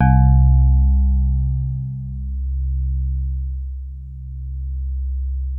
TINE HARD C1.wav